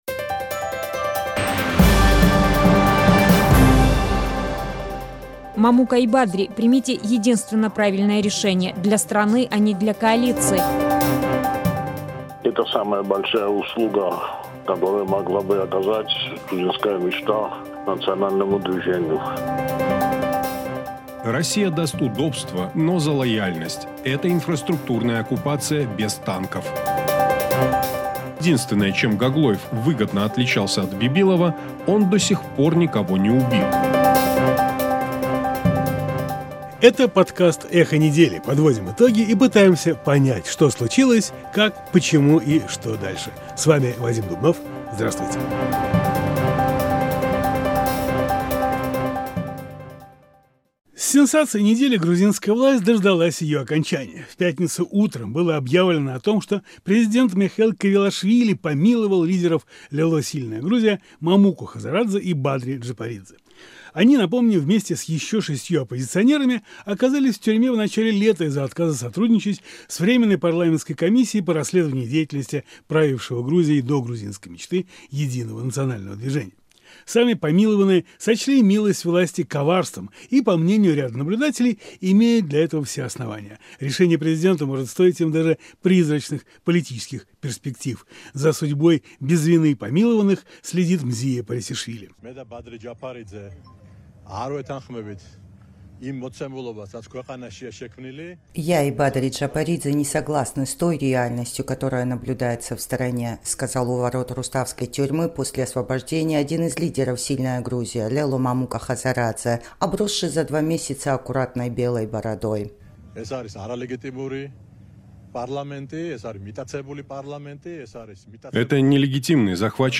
Эхо недели - еженедельный итоговый информационно-аналитический подкаст на Радио "Эхо Кавказа"